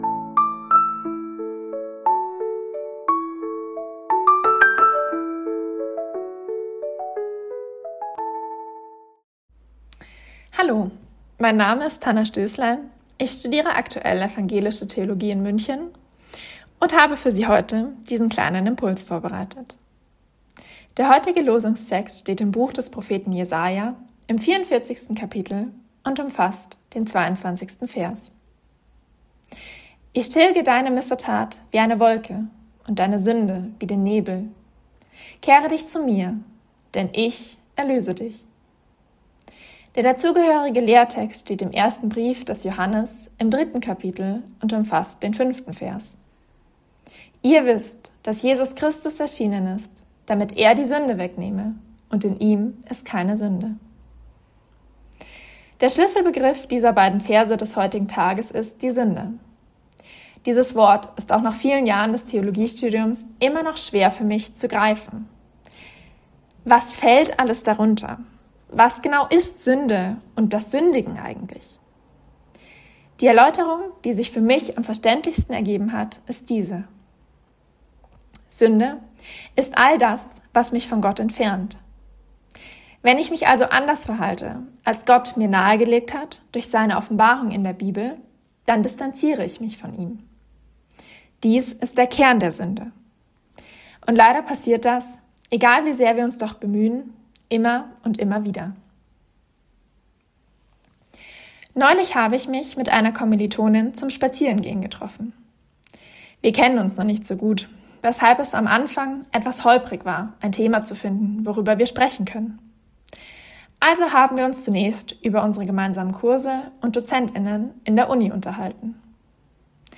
Losungsandacht für Donnerstag, 12.02.2026 – Prot.